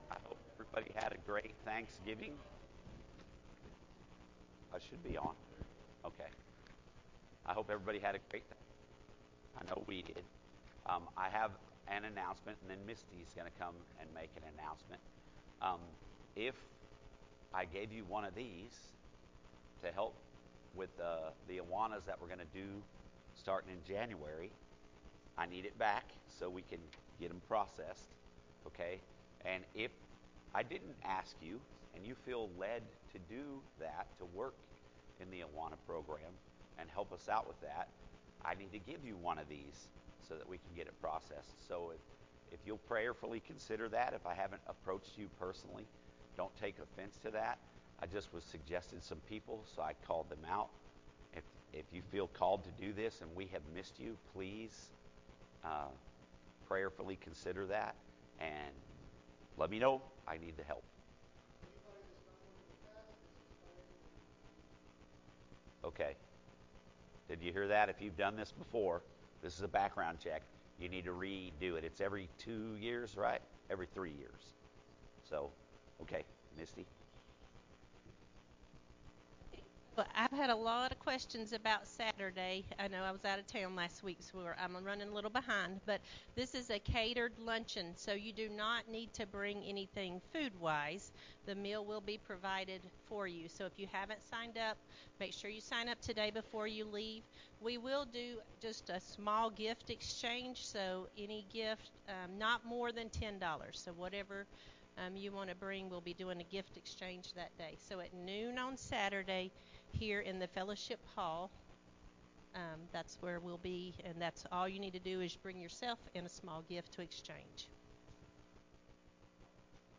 Sunday Morning Sermon Calvary Baptist Church